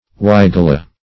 Weigela \Wei"gel*a\, Weigelia \Wei*ge"li*a\, n. [NL.